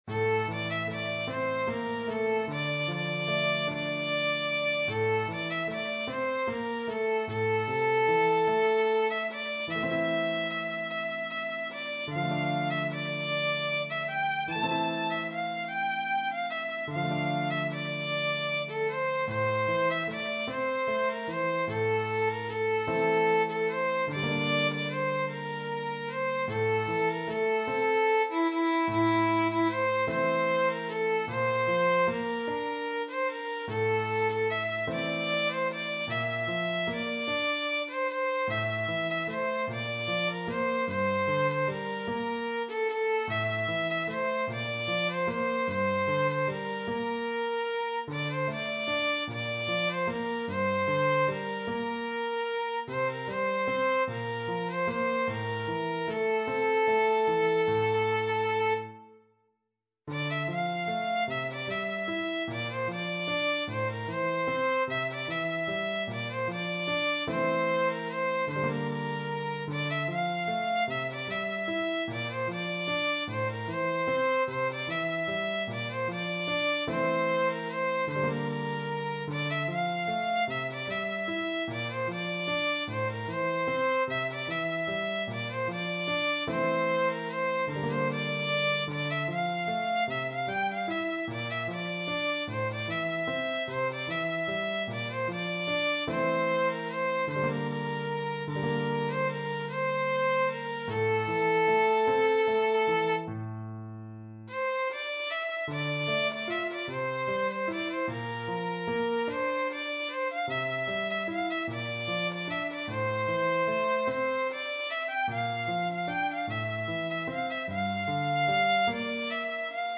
ویولون
سطح : متوسط